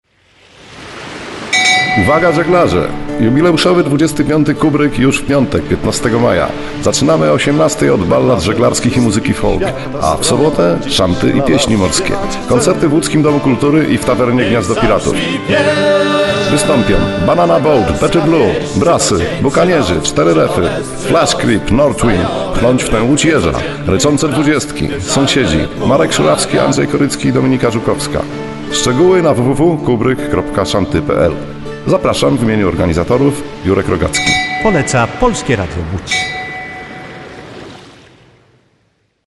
spotu reklamowego.